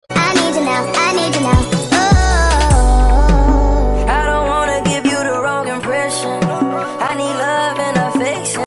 cleaning up your skates after sound effects free download